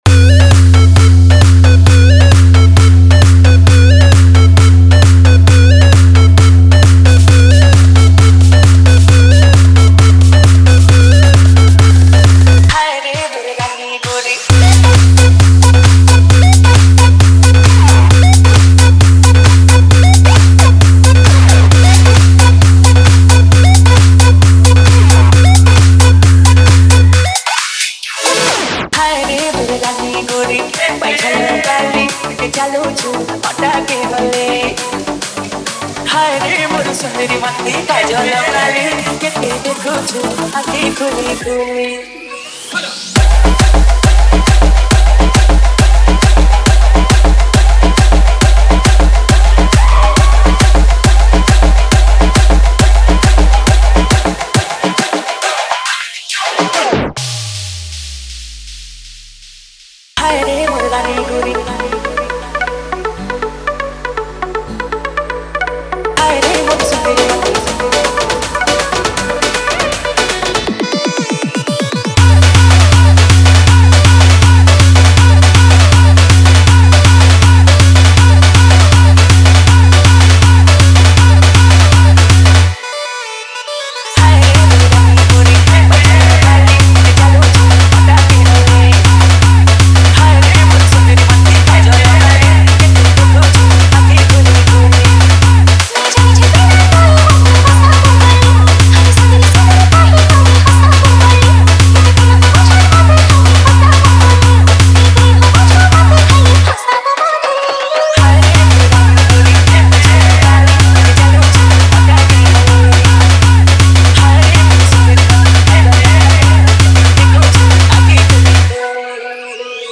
Ganesh Puja Special Dj 2022